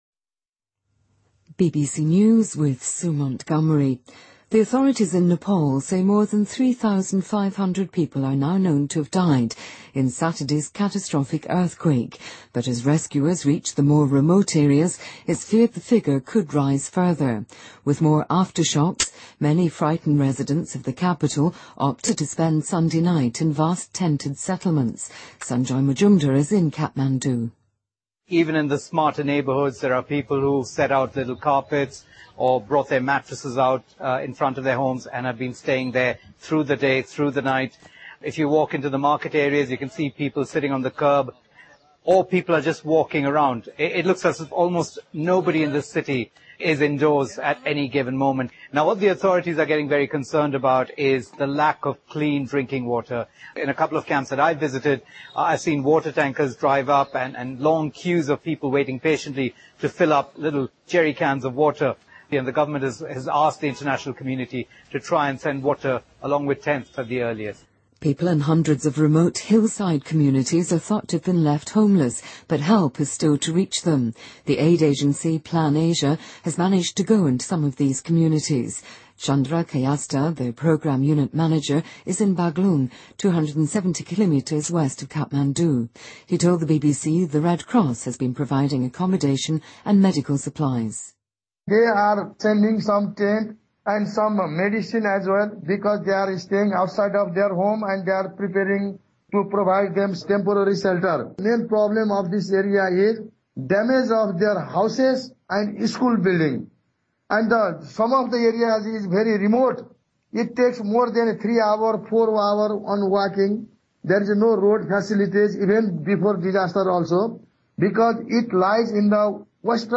BBC news,德国联邦情报局(BND)帮助美国国家安全局(NSA)对欧洲进行监控